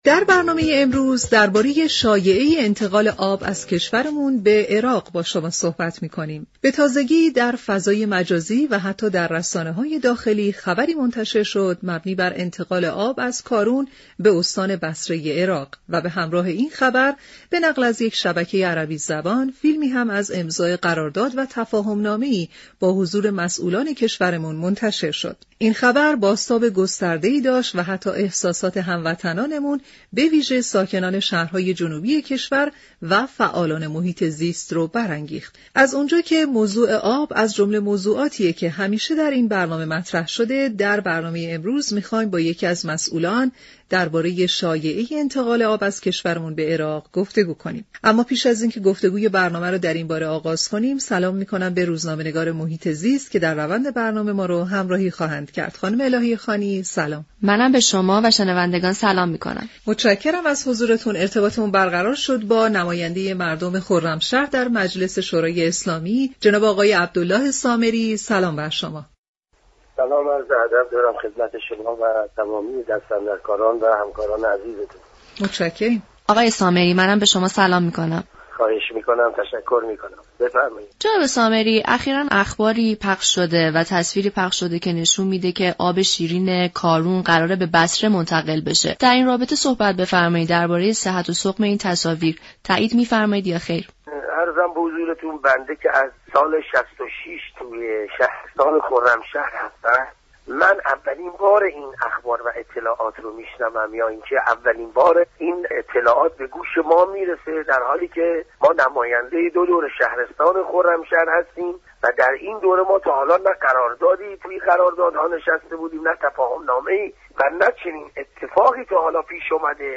سامری نماینده مردم خرمشهر در مجلس شورای اسلامی در گفت و گو با برنامه «سیاره آبی» گفت